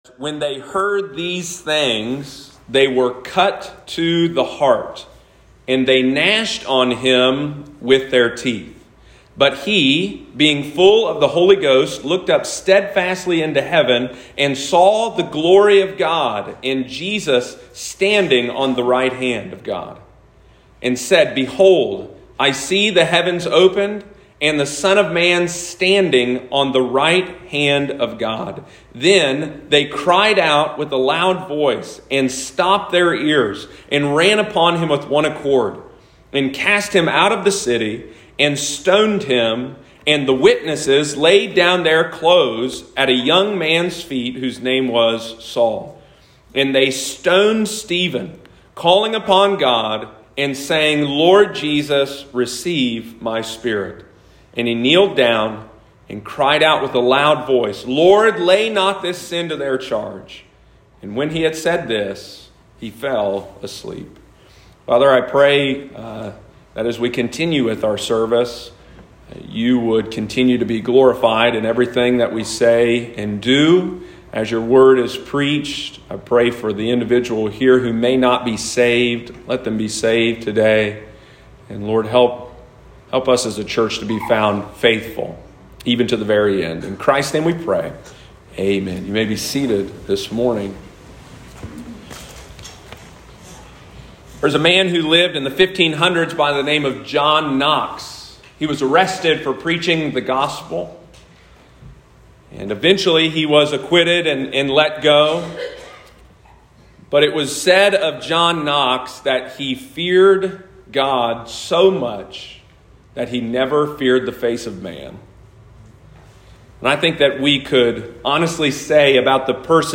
Sunday morning, April 24, 2022.